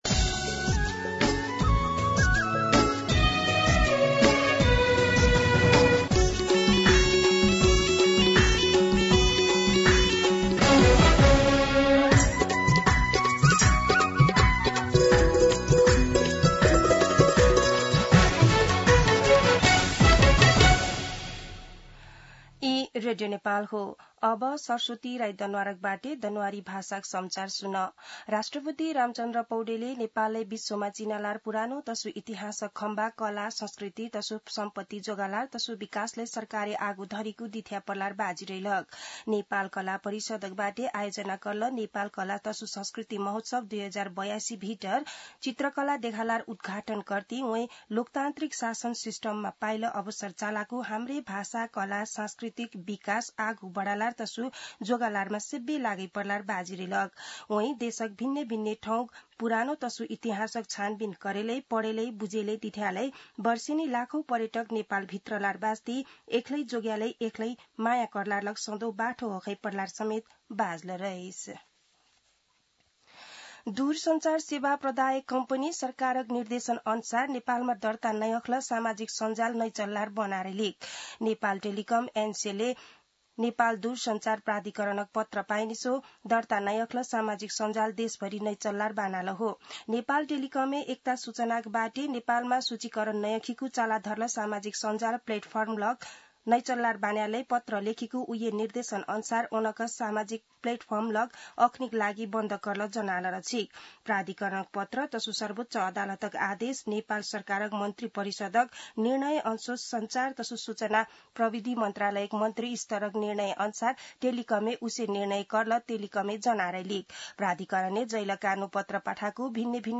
दनुवार भाषामा समाचार : २० भदौ , २०८२
Danuwar-News.mp3